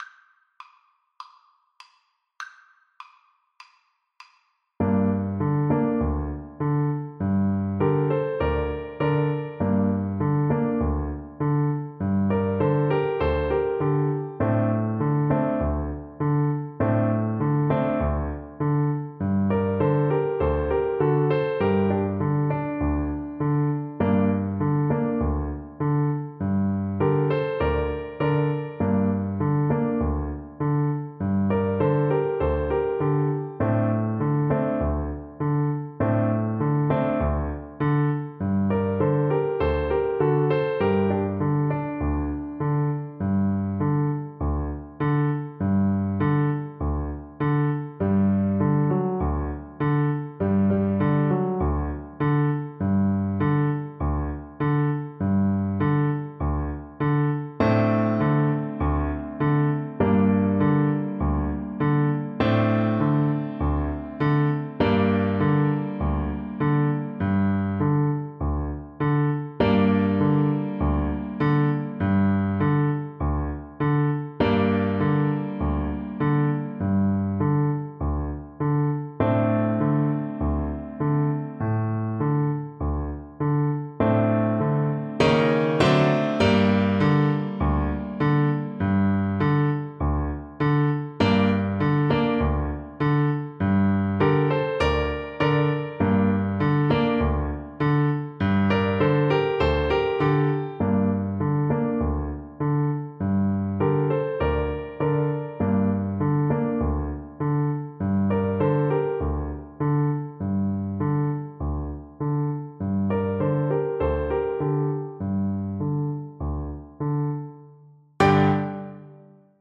Animato